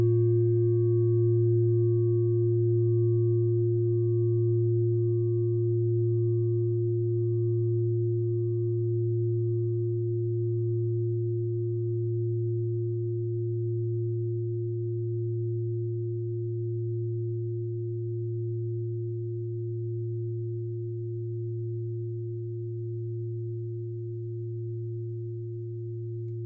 Klangschale TIBET Nr.14
Sie ist neu und ist gezielt nach altem 7-Metalle-Rezept in Handarbeit gezogen und gehämmert worden.
(Ermittelt mit dem Filzklöppel oder Gummikernschlegel)
Der Marston liegt bei 144,72 Hz, das ist nahe beim "D".
klangschale-tibet-14.wav